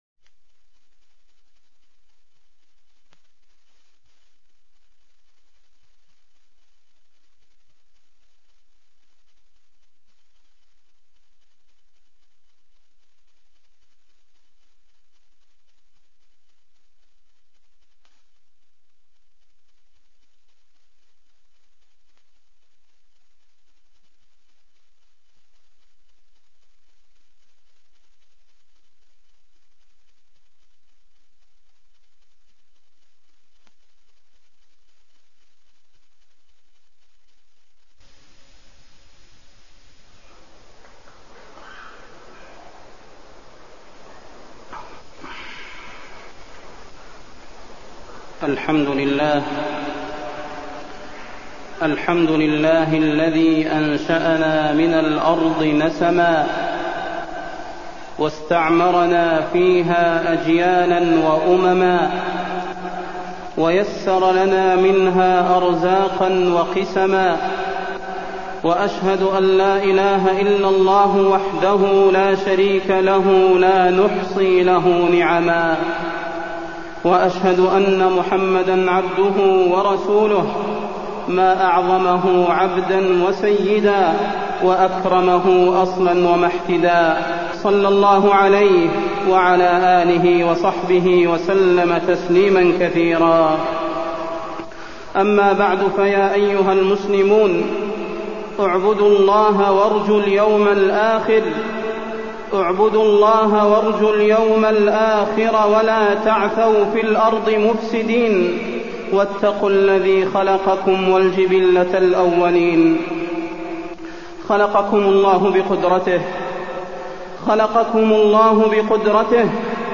تاريخ النشر ١ ربيع الأول ١٤٢٤ هـ المكان: المسجد النبوي الشيخ: فضيلة الشيخ د. صلاح بن محمد البدير فضيلة الشيخ د. صلاح بن محمد البدير الغفلة والبعد عن الله